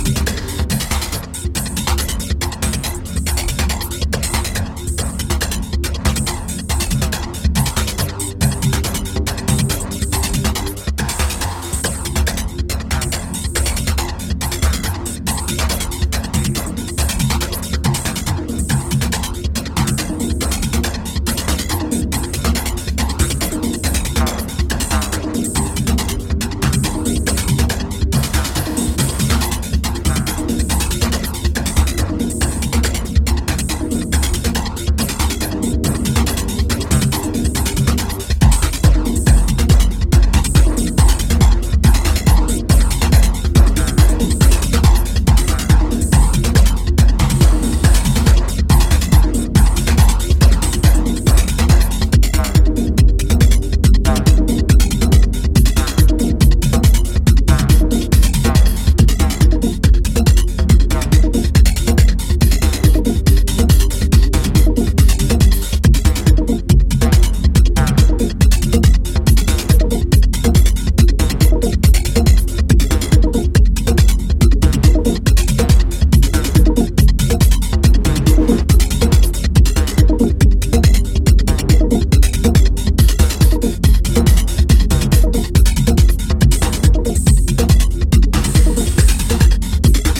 Detroit techno-soul music